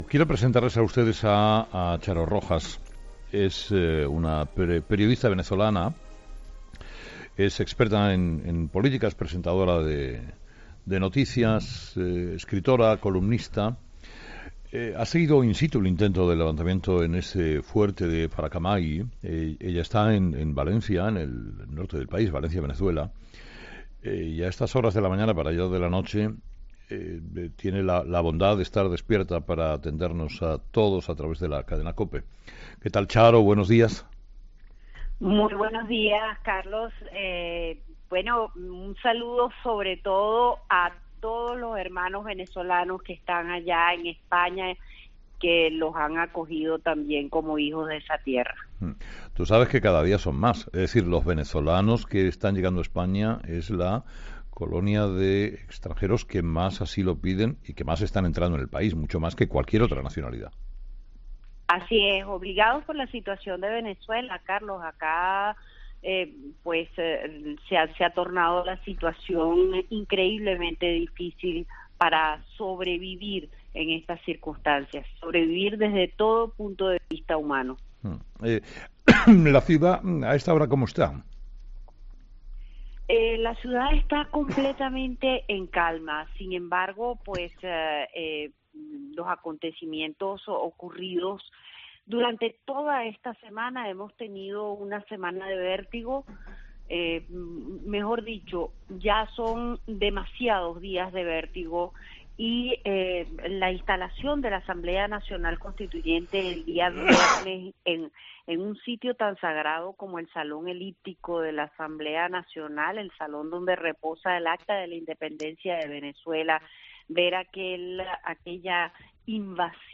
La periodista venezolana